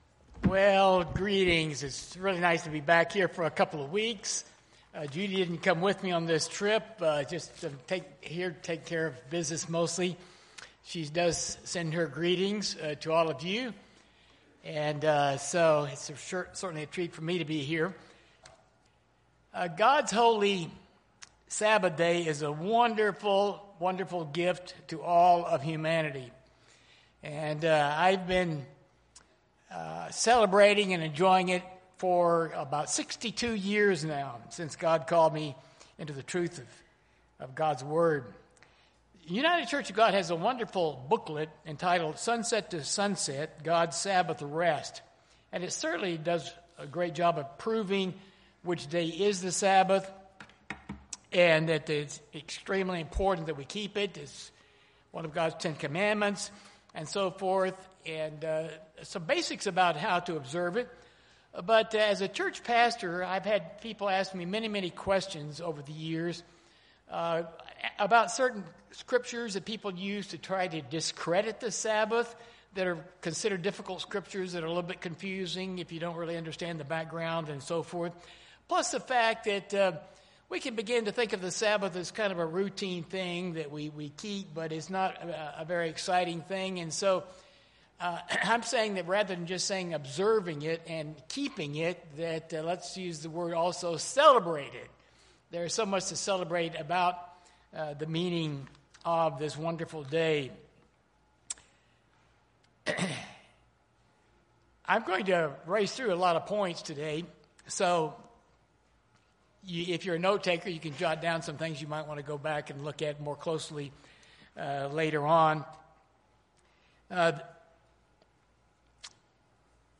This sermon addresses many of the common questions and stresses the importance of joyful celebration of the Sabbath.
Given in Dallas, TX